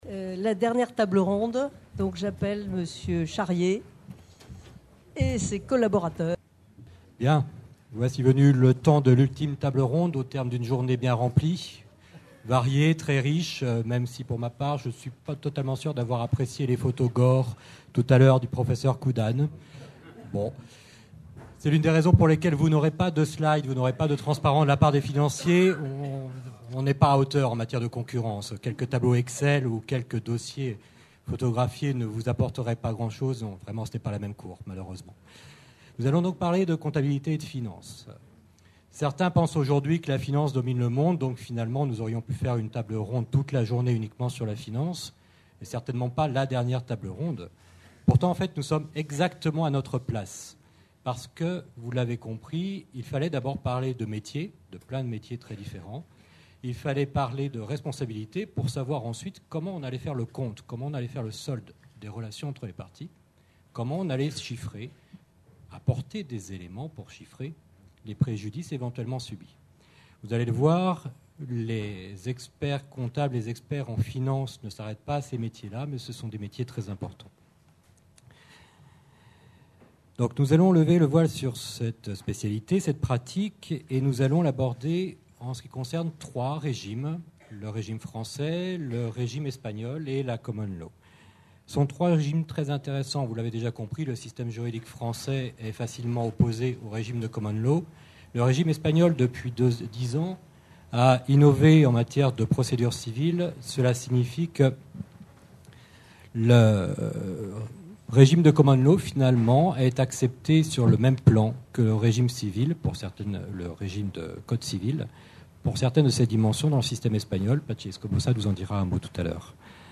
Colloque des Compagnies des Experts de Justice du Grand Est. Organisé par la Compagnie de Reims sous la présidence d’honneur de Monsieur le Premier Président et de Monsieur le Procureur Général de la Cour d’Appel de Reims.